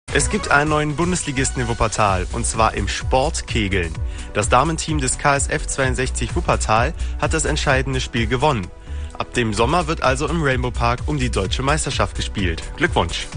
Hier ein Mitschnitt von Radio-Wuppertal Sport Kompakt 16.03.2016